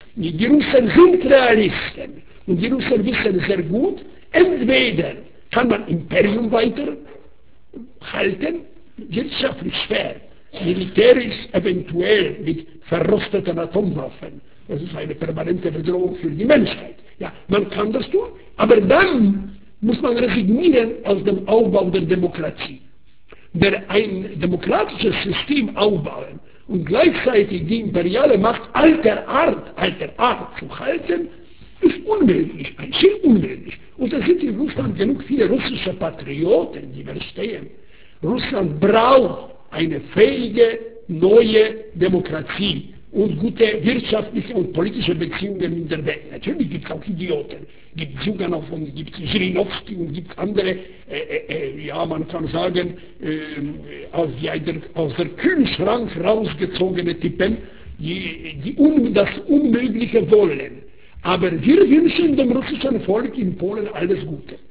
Wladyslav Bartoszewski erzählt am 13. Dezember 1996 im Heinrich-Heine-Institut Düsseldorf: